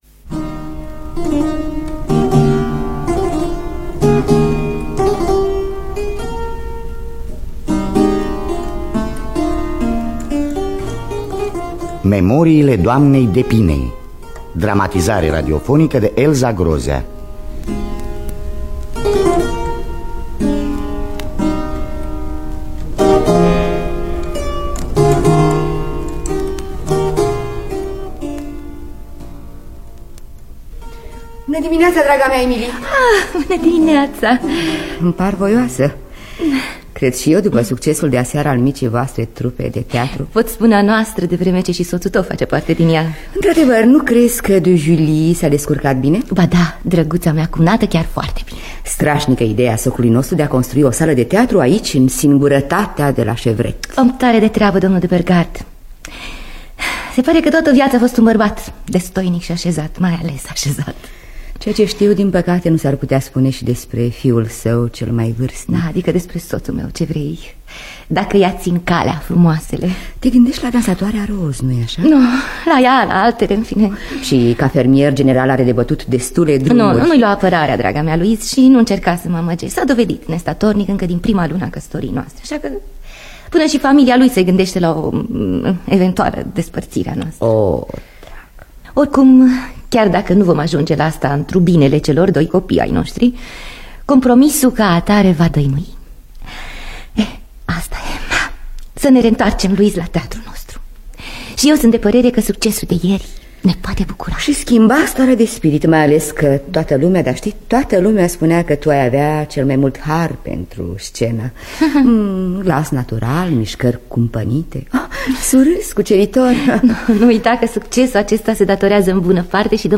Biografii, Memorii: Memoriile Doamnei D’Epinay (1981) – Teatru Radiofonic Online